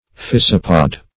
physopod - definition of physopod - synonyms, pronunciation, spelling from Free Dictionary Search Result for " physopod" : The Collaborative International Dictionary of English v.0.48: Physopod \Phy"so*pod\, n. (Zool.) One of the Physopoda; a thrips.